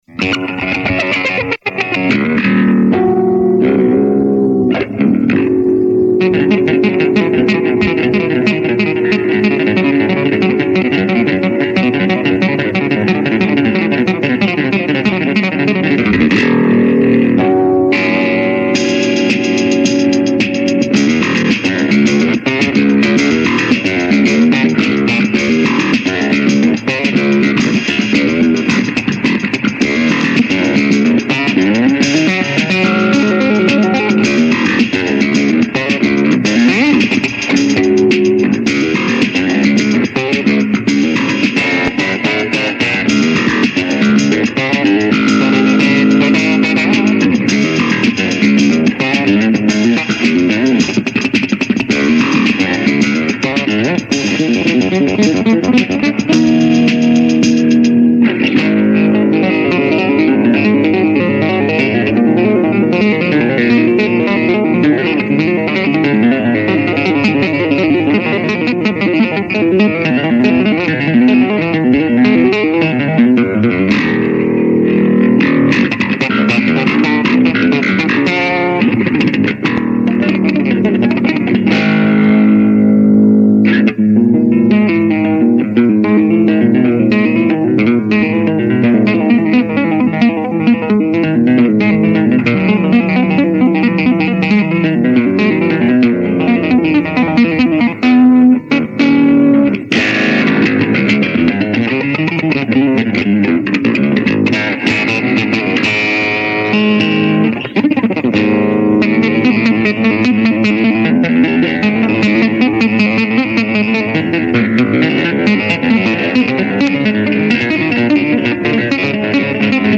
NOTE: bass solo